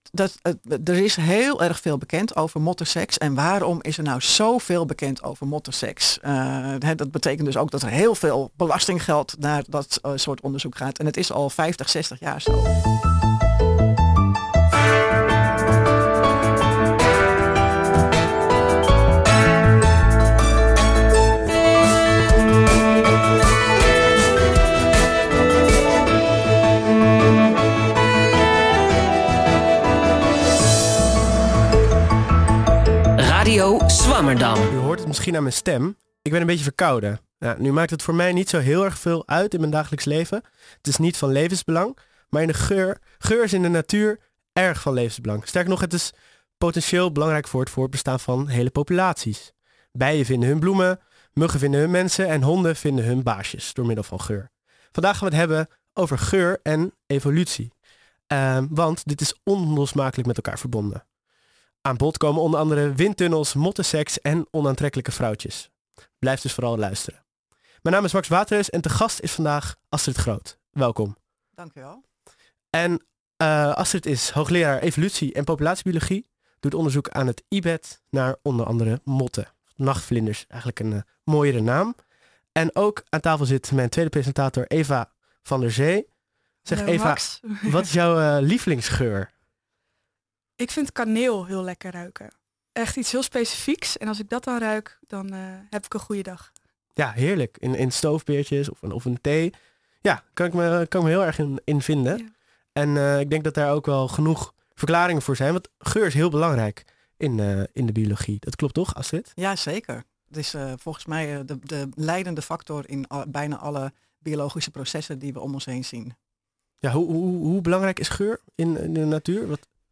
In onze knusse studio in Pakhuis de Zwijger schuiven wetenschappers aan om hun onderzoek uitgebreid en toegankelijk toe te lichten.